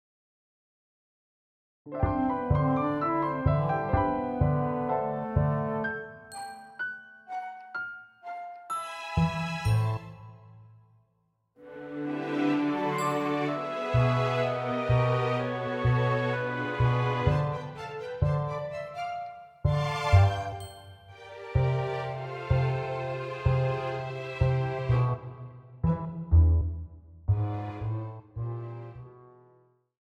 Instrumental Solos Cello